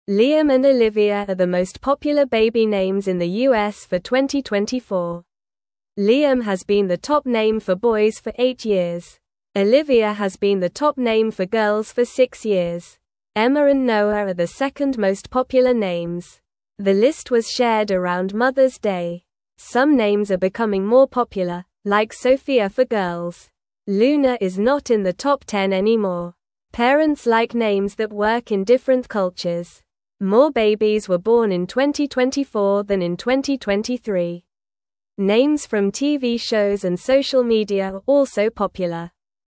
Normal
English-Newsroom-Beginner-NORMAL-Reading-Liam-and-Olivia-Are-Top-Baby-Names-for-2024.mp3